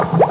Bubble Sound Effect
Download a high-quality bubble sound effect.
bubble.mp3